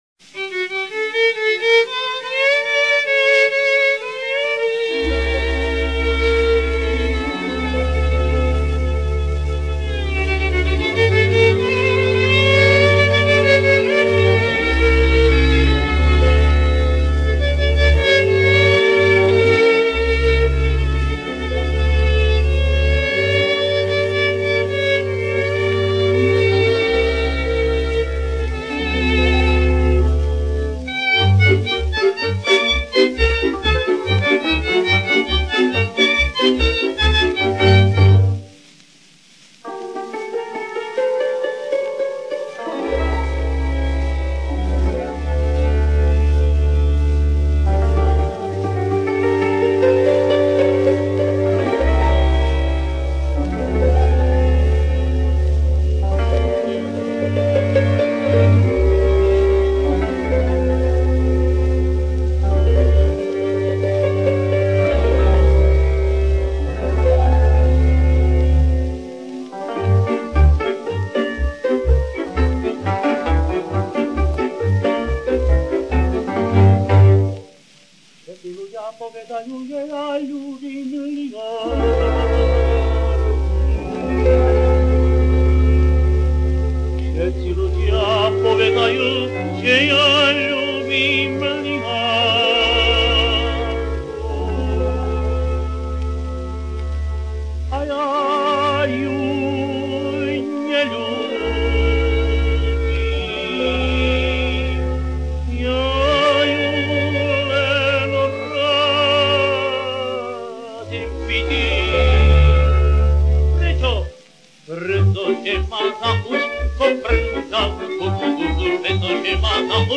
Slovenská ľudová pieseň (podnázov)
Mužský spev so sprievodom cigánskeho orchestra.